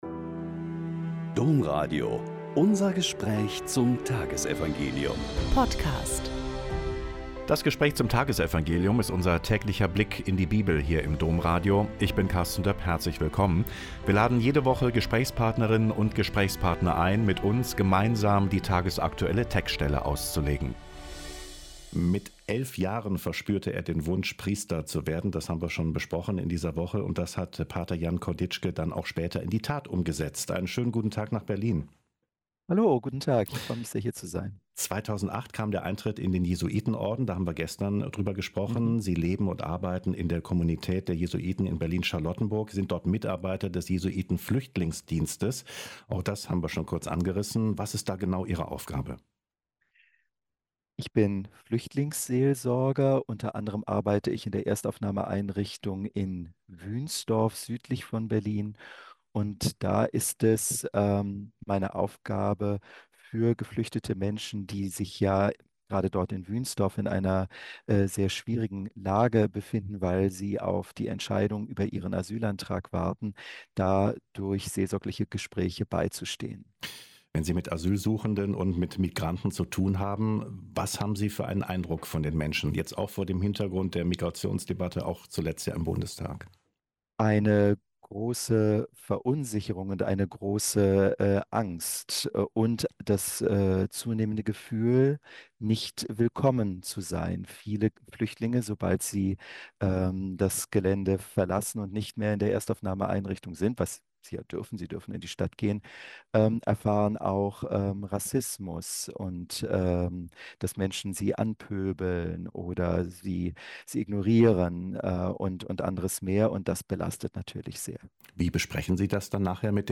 Mk 8,22-26 - Gespräch